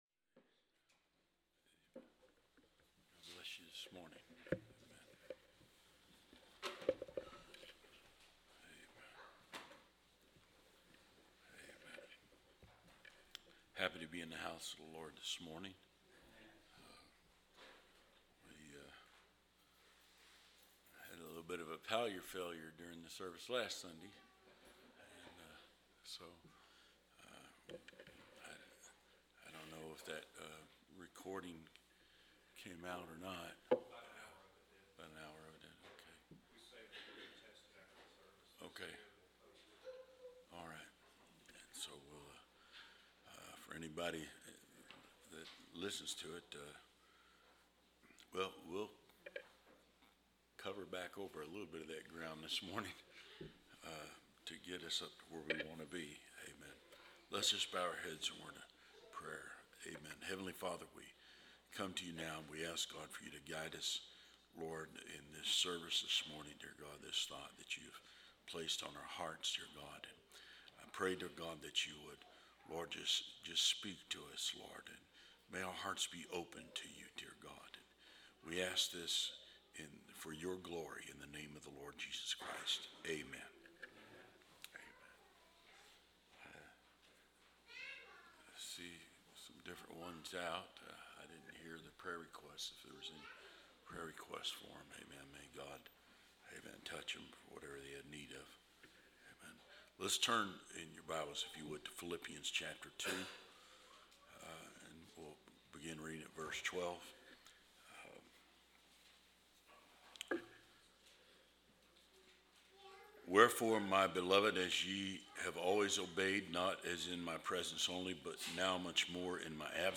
Preached November 24, 2024